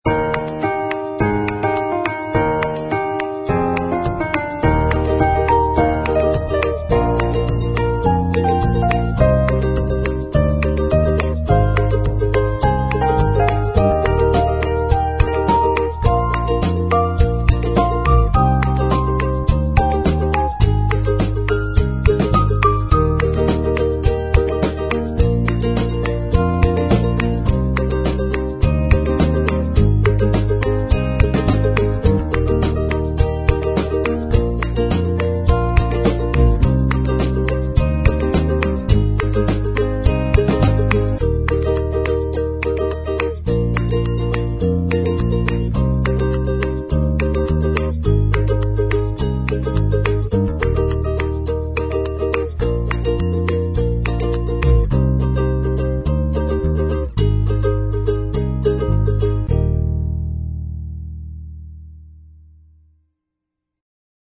描述：通过公司类别中新的激励轨迹来推广你的项目